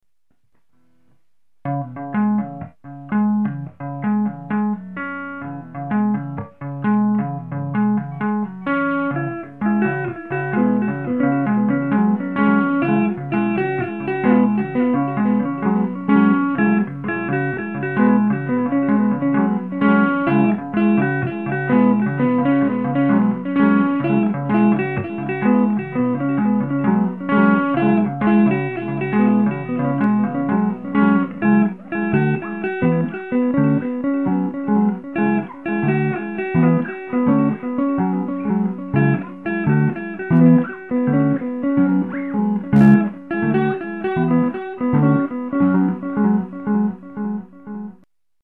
a 48-second demo